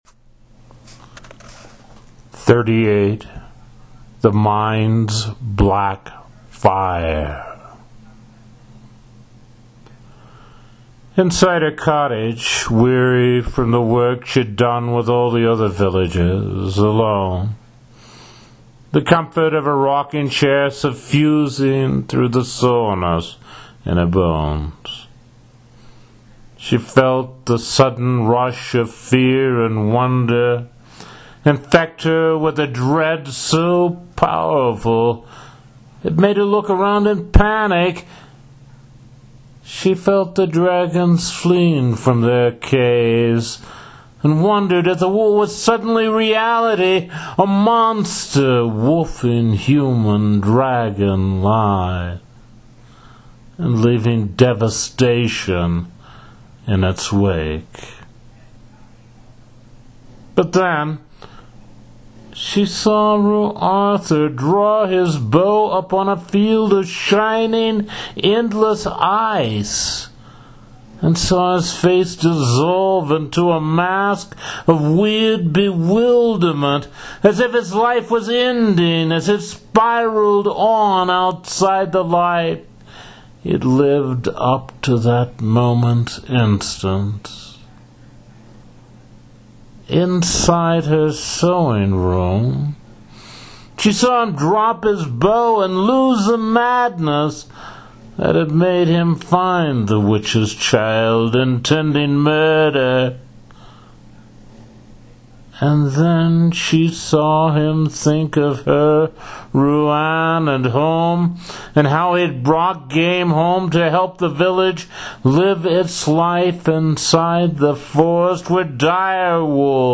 Very moving to hear it read aloud, and I’m still recovering from the last segment.